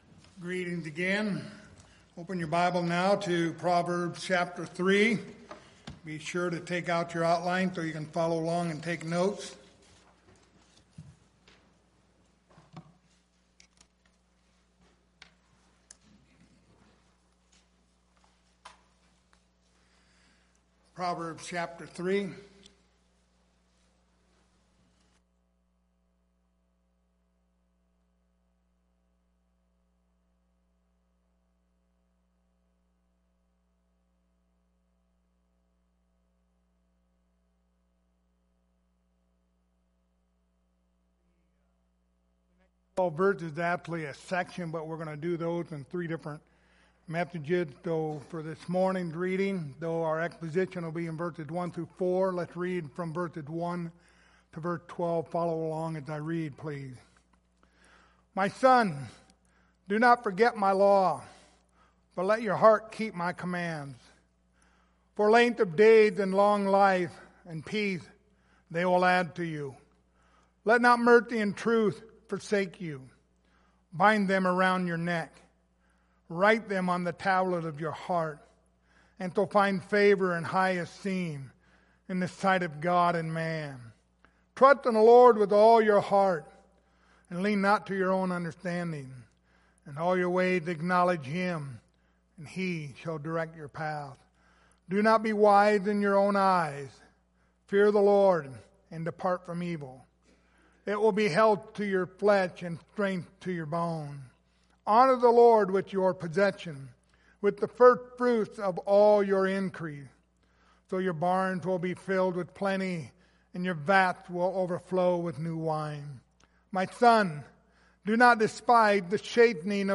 The Book of Proverbs Passage: Proverbs 3:1-4 Service Type: Sunday Morning Topics